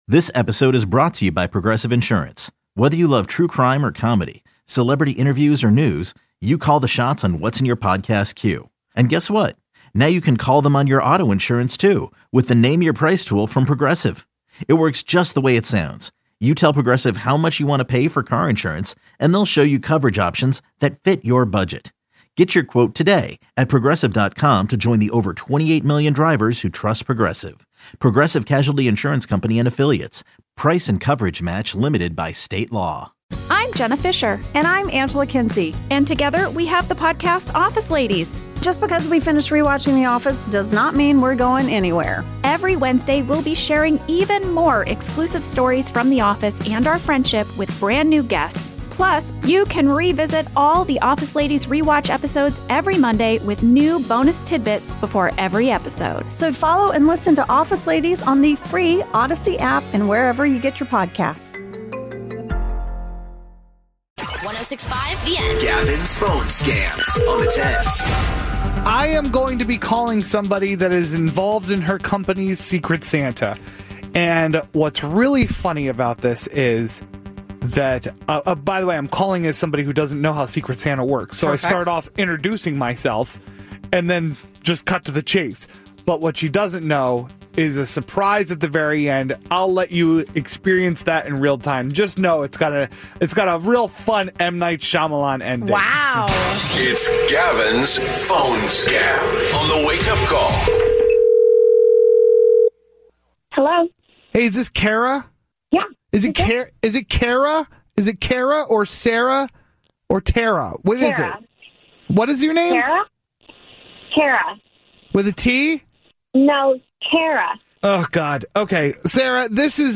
Comedy Entercom Communications Corp Wake